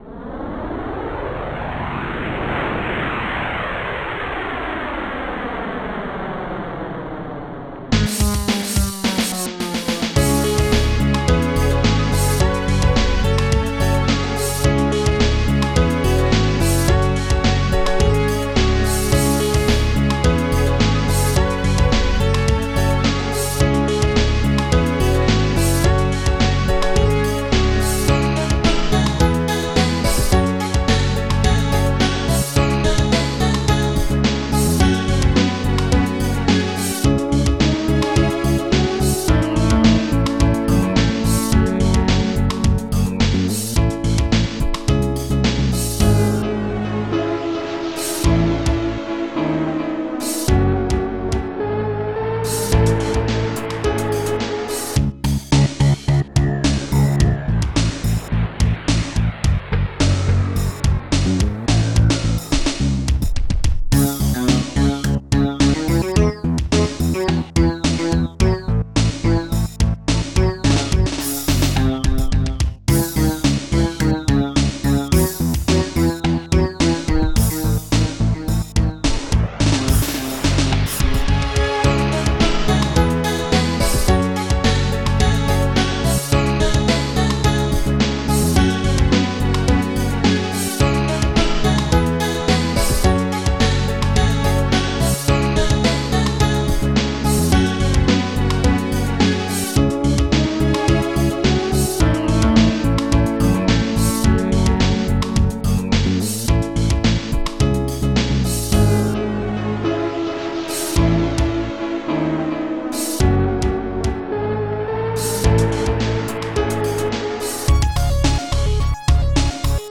s3m (Scream Tracker 3)